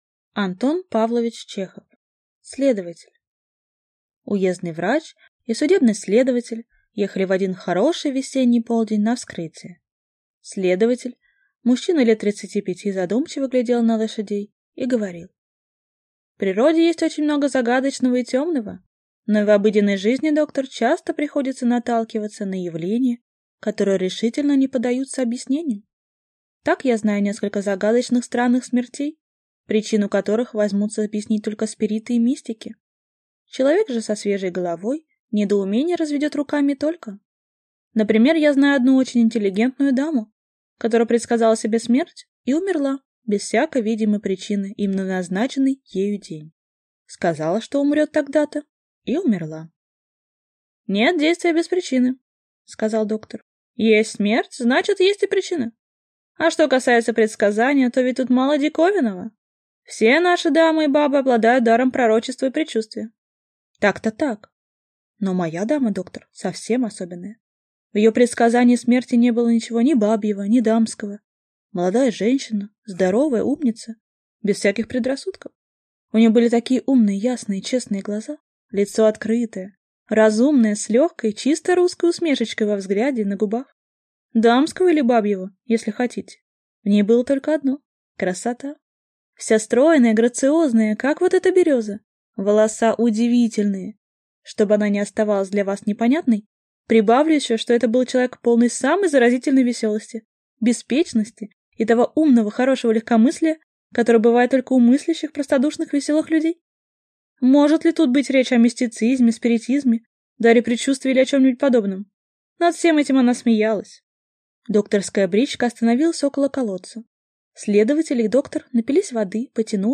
Аудиокнига Следователь | Библиотека аудиокниг